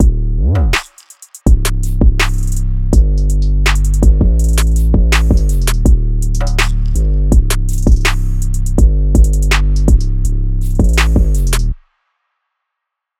drumloop 12 (82 bpm).wav